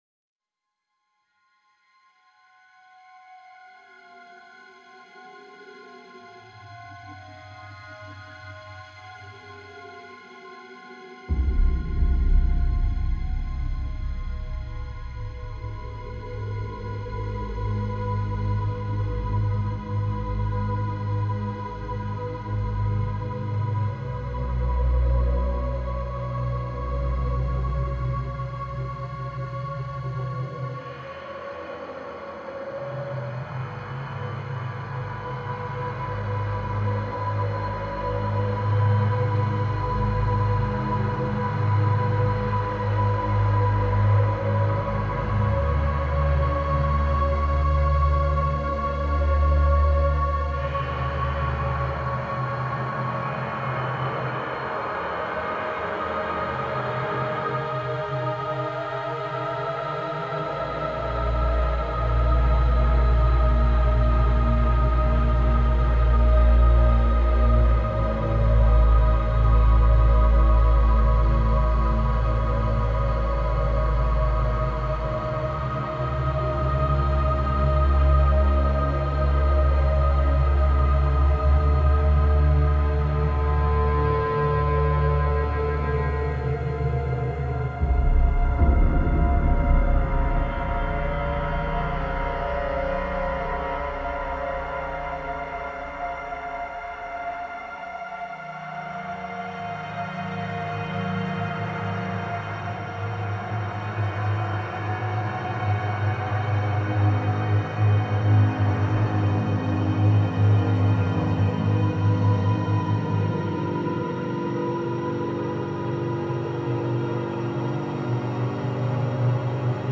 Ambience 1 - The Entrance.wav